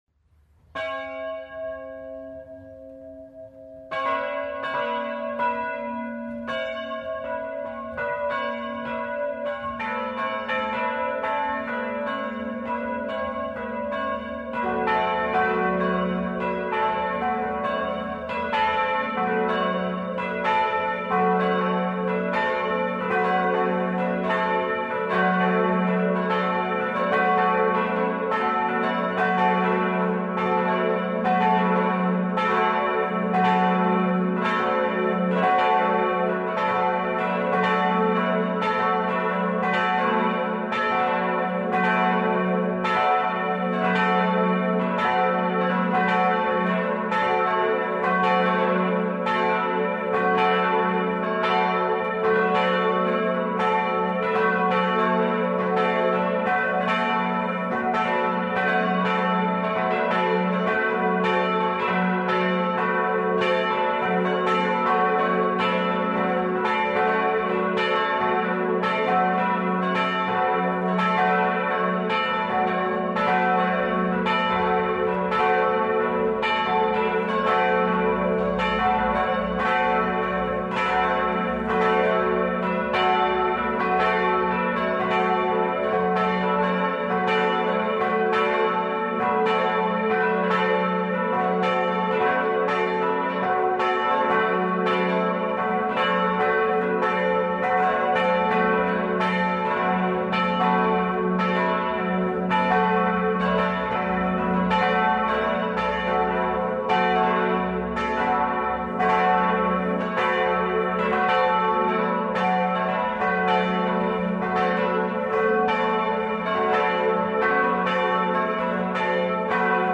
Vorab können Sie hier das Glockengeläut der Ebracher Kirche hören: Glockengeläut Ebrach (ca. 5 MB)
glockengelaeut1.mp3